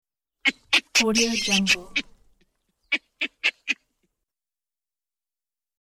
Baboon Sound Button - Free Download & Play
Monkey Sounds44 views